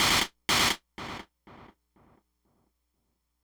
synthFX01.wav